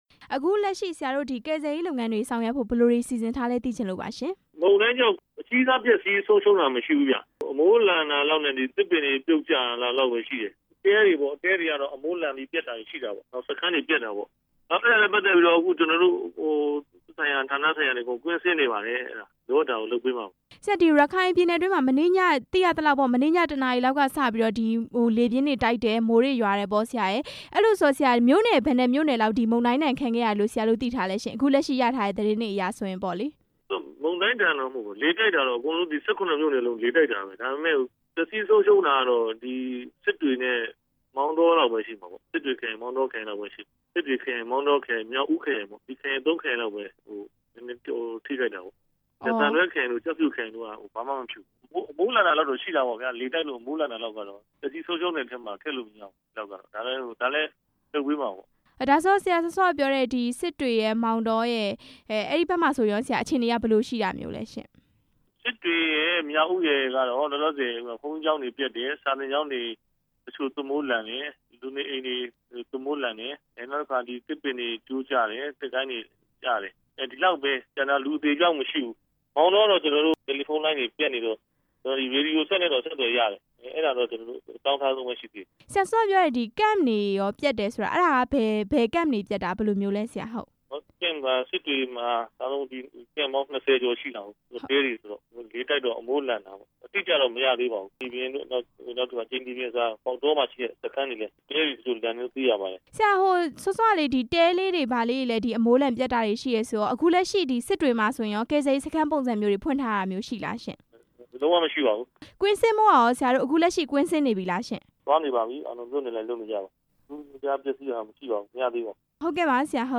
မိုရာ ဆိုင်ကလုန်းအကြောင်း မေးမြန်းချက်